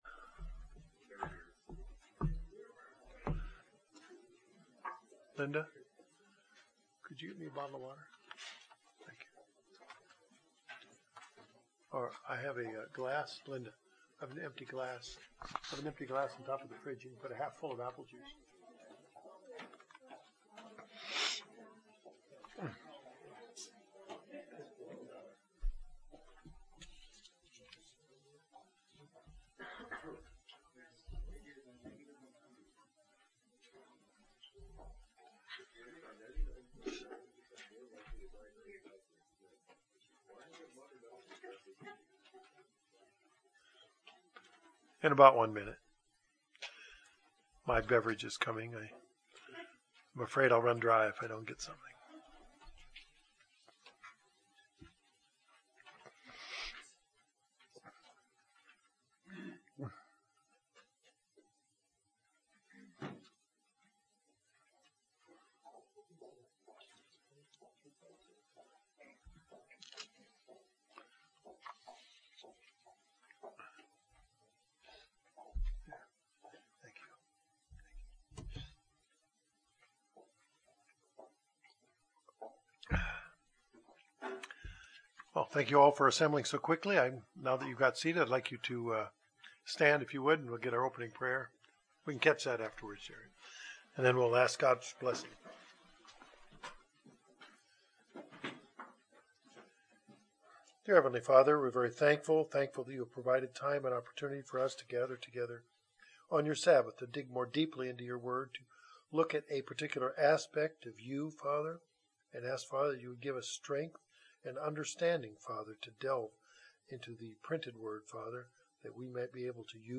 This begins a two to three part Bible Study series using the study aid, "Who Is God?," to discover how the Bible identifies God and how God relates to humanity.